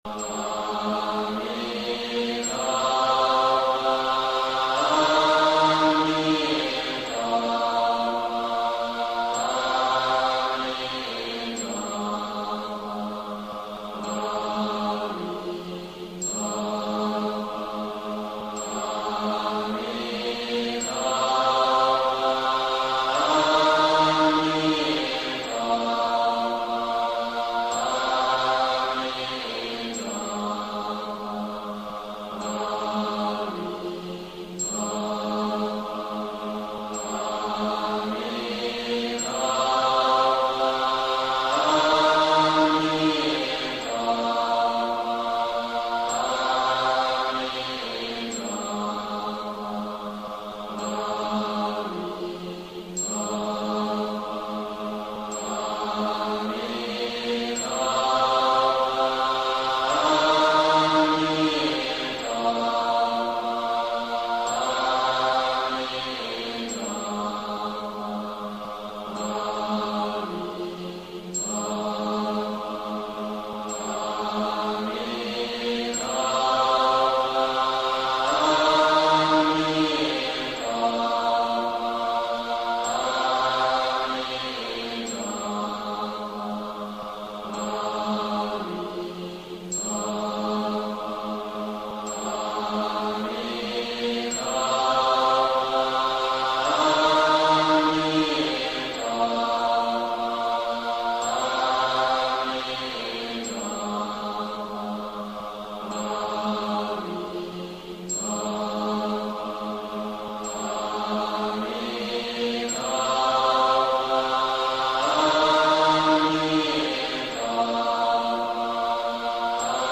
(影)阿彌陀佛 聖號 四字四音 不間斷修行版